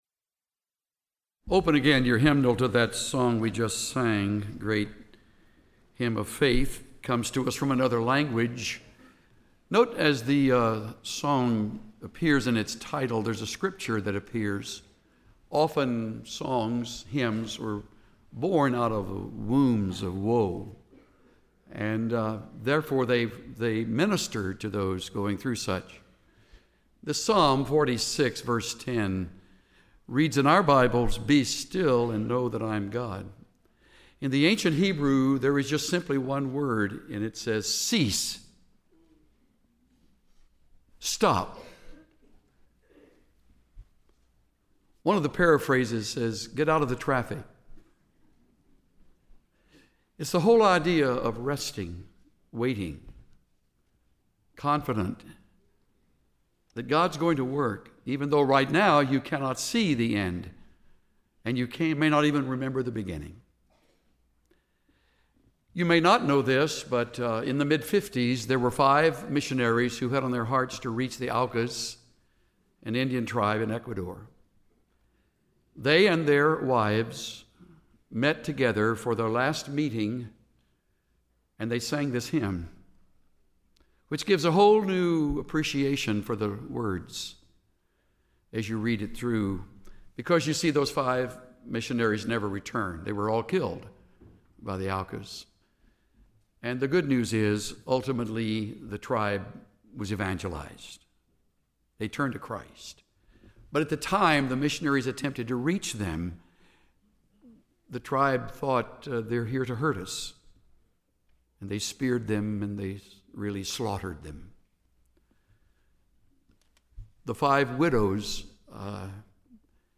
We deeply desire for you to know the absolute joy and complete freedom that come from living in God’s grace. And we know you’ll love the special rendition of “Amazing Grace” at the end of the message.